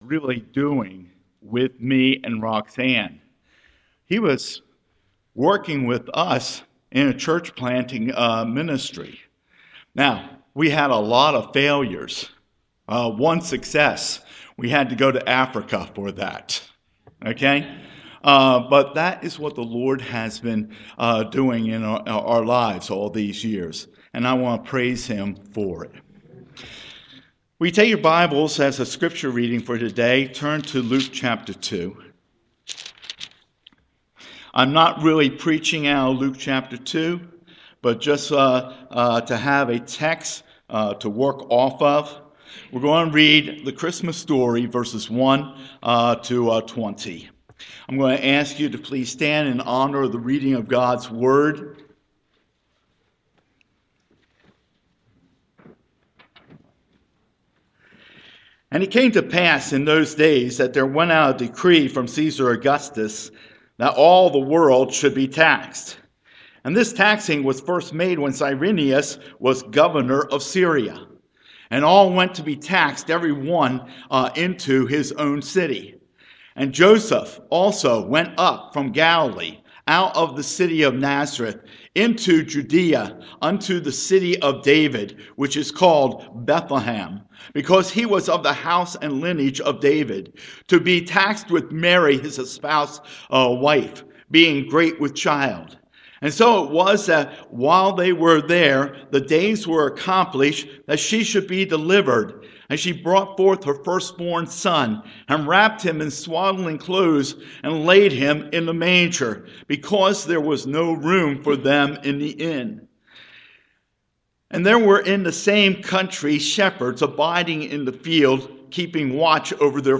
Morning Worship
Sermon